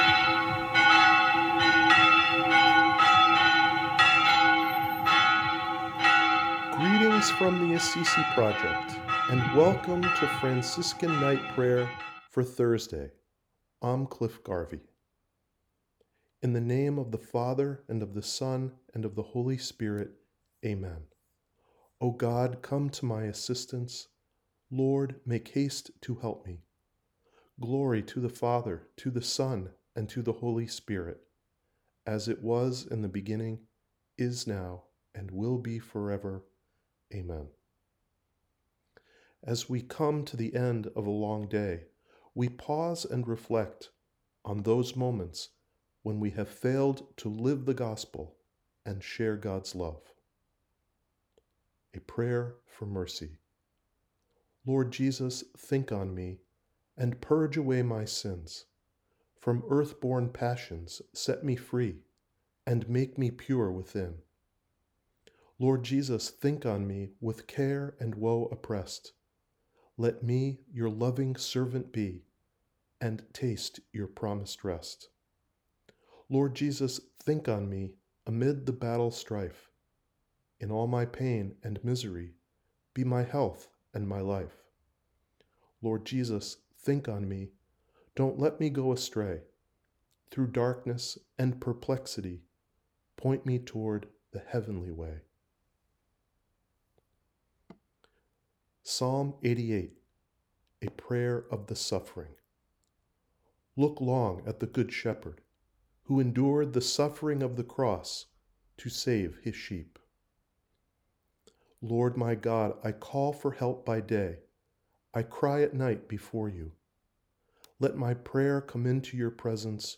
ap-fri-night-prayer.wav